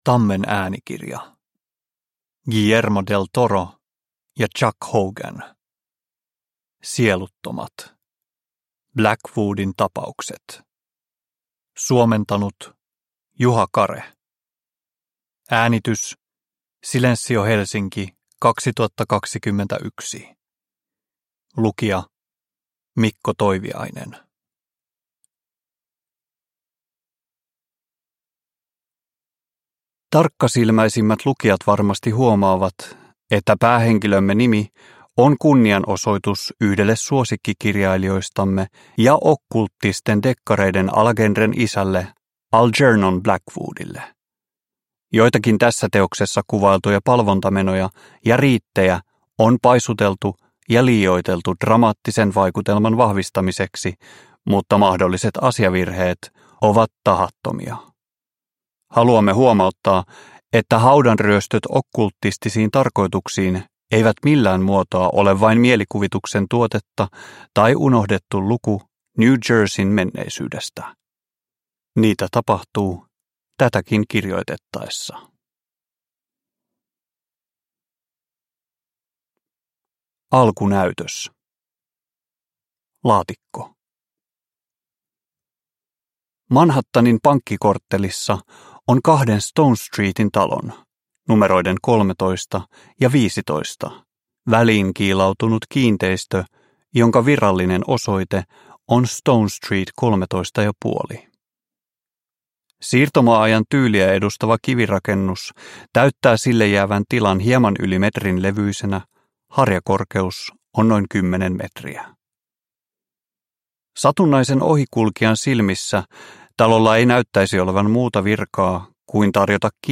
Sieluttomat – Ljudbok – Laddas ner